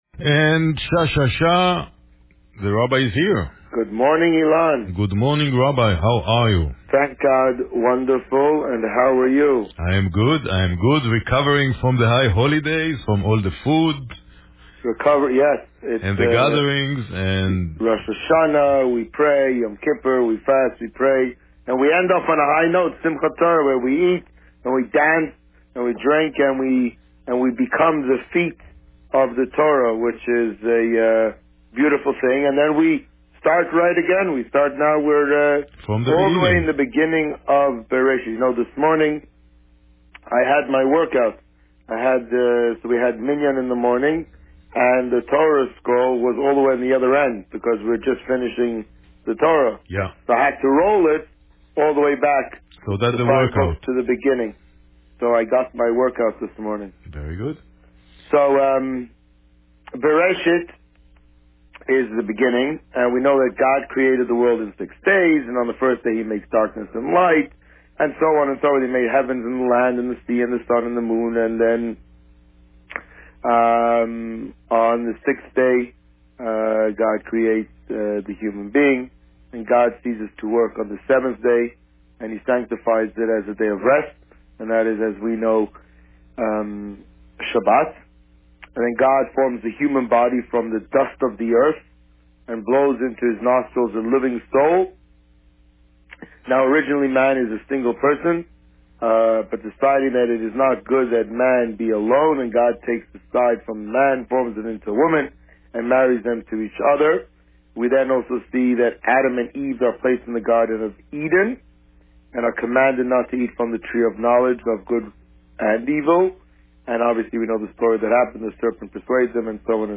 This week, the Rabbi spoke about the end of the High Holidays and Parsha Bereishit. Listen to the interview here.